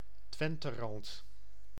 Twenterand (Dutch: [ˈtʋɛntərɑnt]